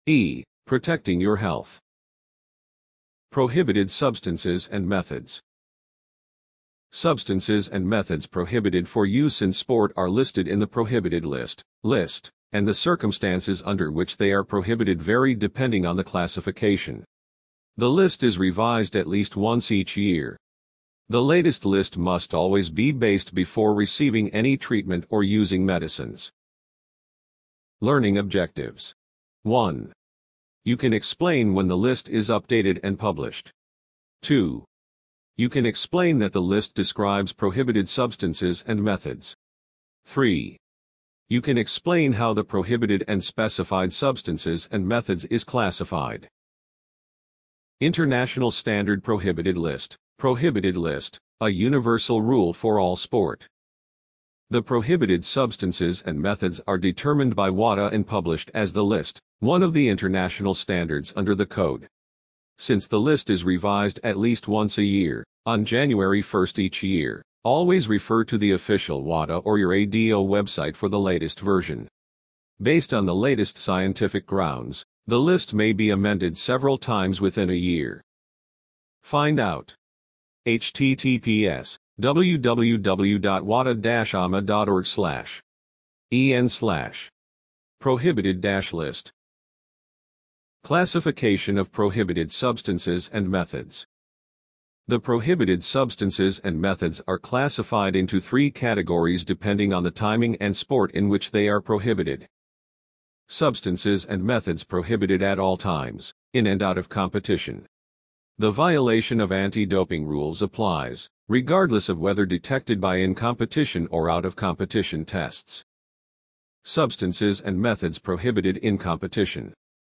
Introduction(P1-8) Audio Digital Book Audio Only(Page1to8) A. Values of Sport and Clean Sport Environment.